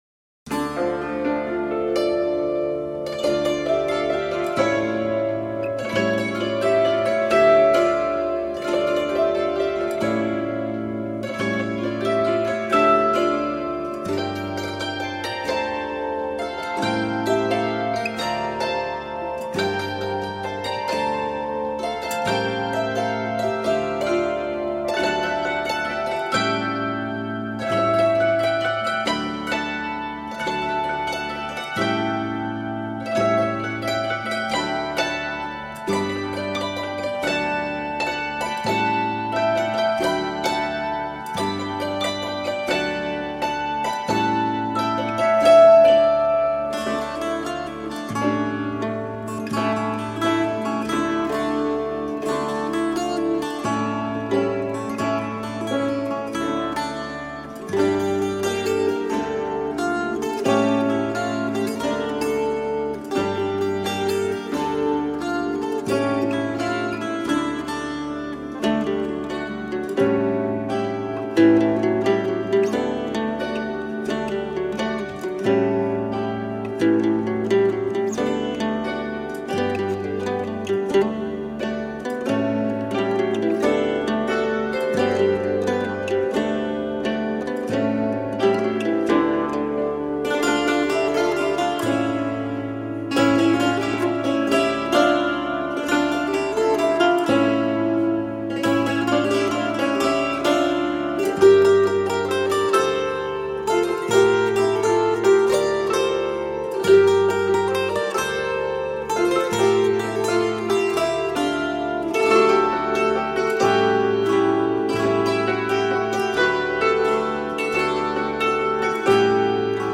Smile-inducing, toe-tapping folkgrass.